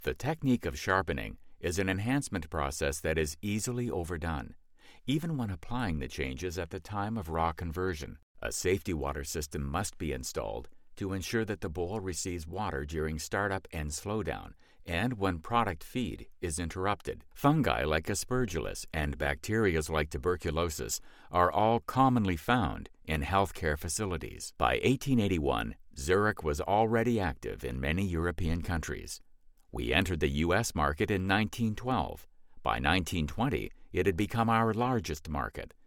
Voice Over; VO; Narrations;
mid-atlantic
Sprechprobe: eLearning (Muttersprache):
Warm, Friendly, Authoritative.